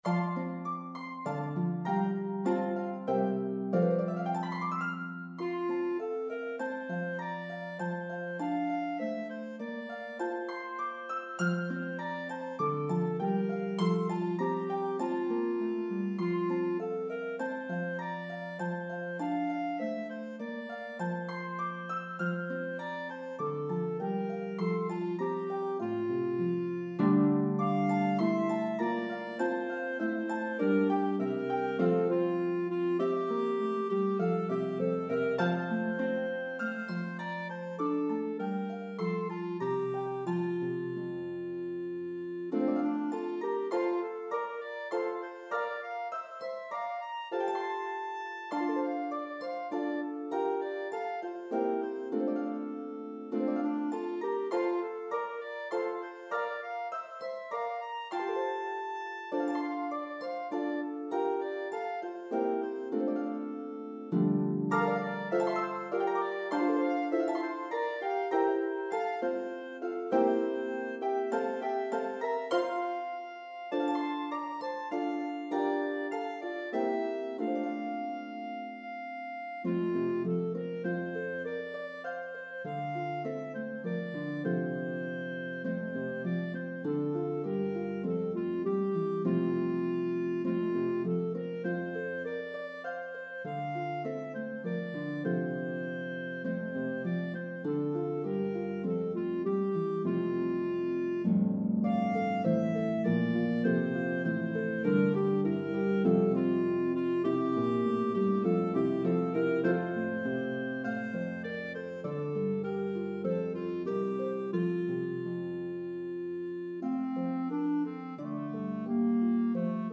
The exuberant French Carol